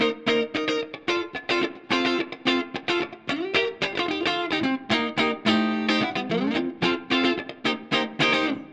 30 Guitar PT4.wav